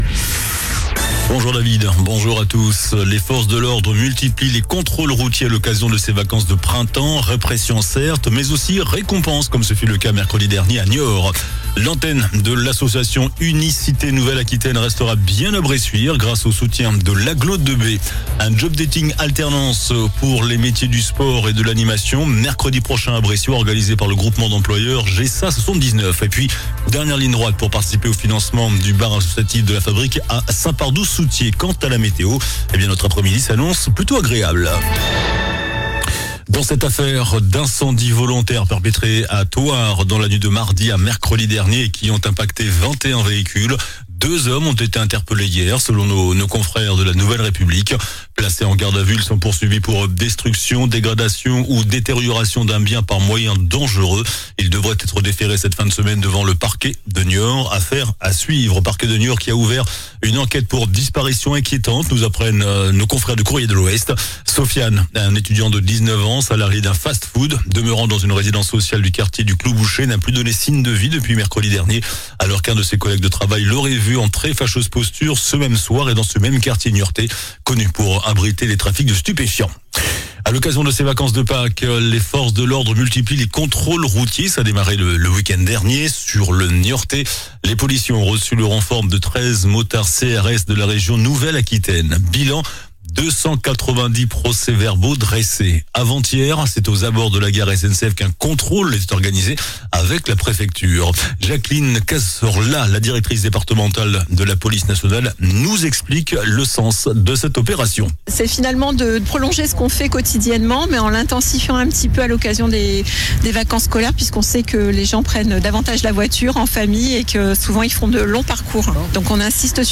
JOURNAL DU VENDREDI 25 AVRIL ( MIDI )